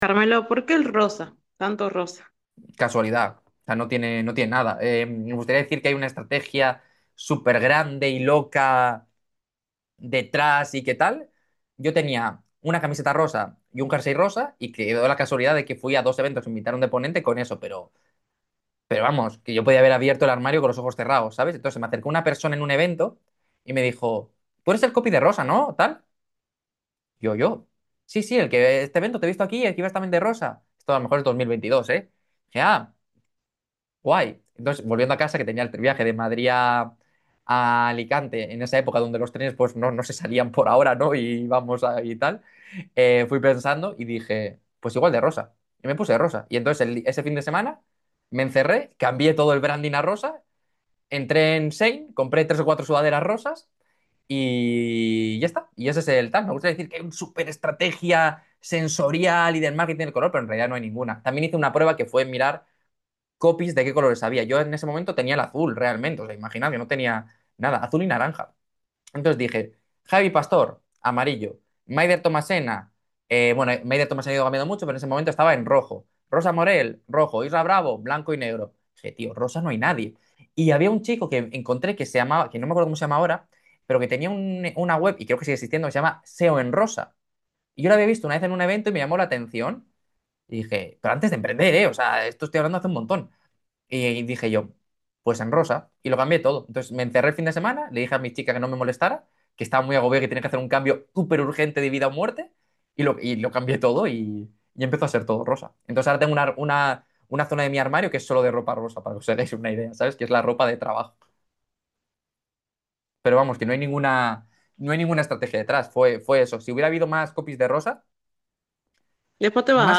¿Por qué he elegido el color rosa como color de marca y qué importancia le doy para la venta? ¡Lo analizamos en directo!